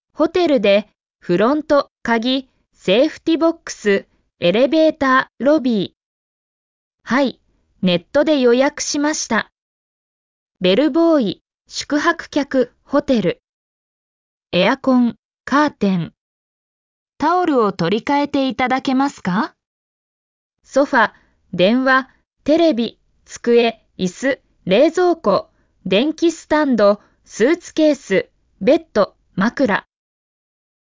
日本語読み
声：音読さん